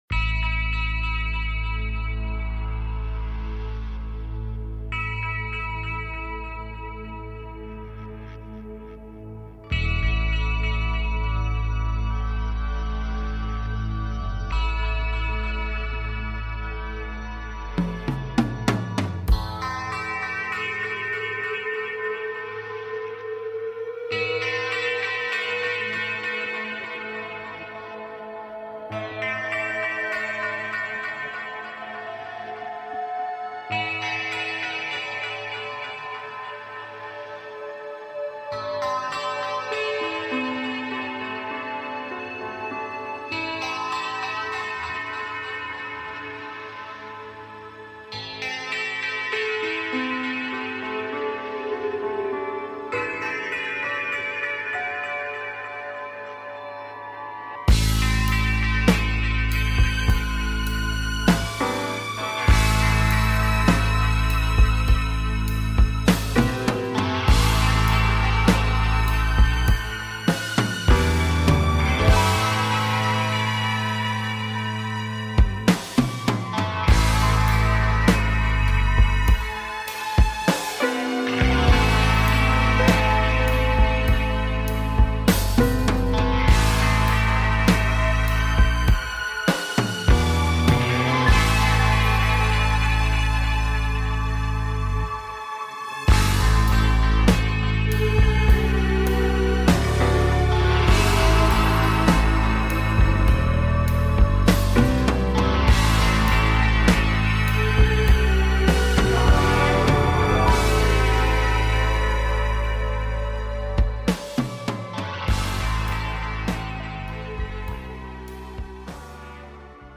Talk Show Episode
Reviewing listener projects and answering listener calls